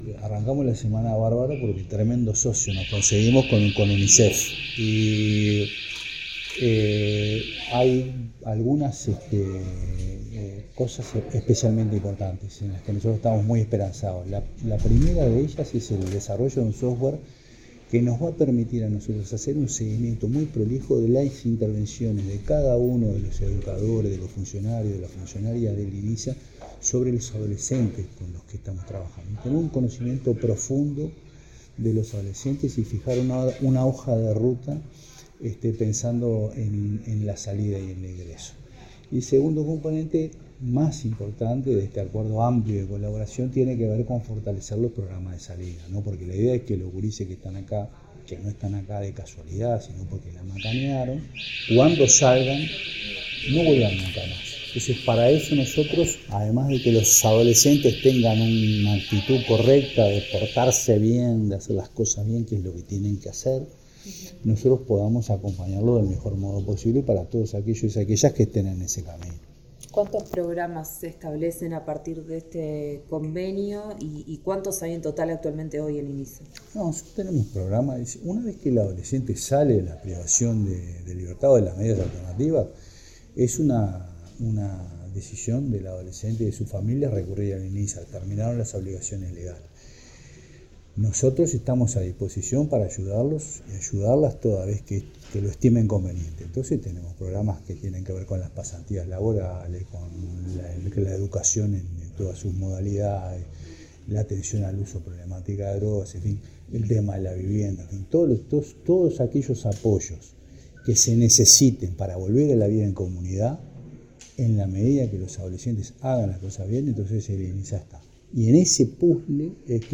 Declaraciones del presidente del Inisa, Jaime Saavedra
Declaraciones del presidente del Inisa, Jaime Saavedra 20/10/2025 Compartir Facebook X Copiar enlace WhatsApp LinkedIn El presidente del Instituto Nacional de Inclusión Social Adolescente (Inisa), Jaime Saavedra, realizó declaraciones en el marco de la firma de un acuerdo con Unicef Uruguay.